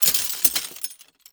GLASS_Fragments_Fall_02_mono.wav